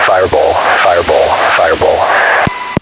Fireball.ogg